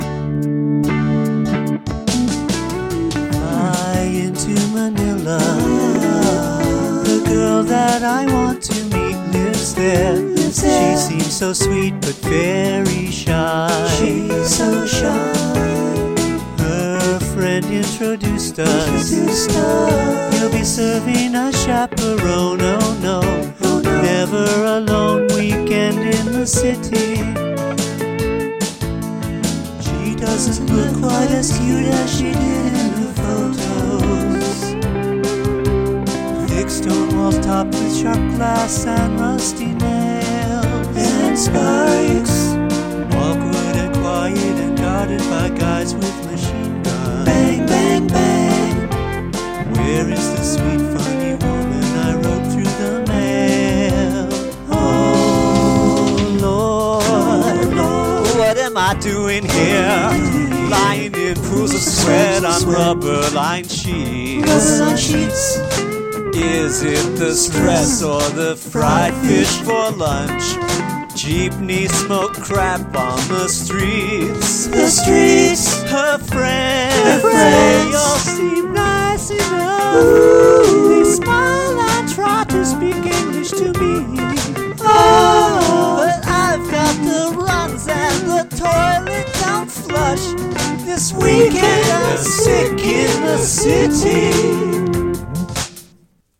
Epic story song